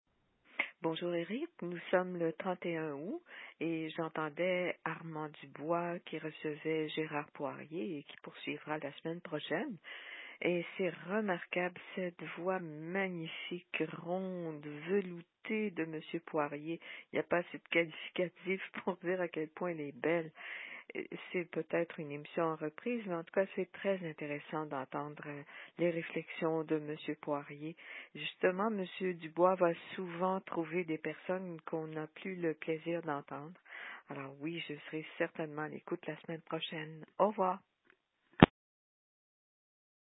Entrevue radio avec Gérard Poirier (1930-2021) .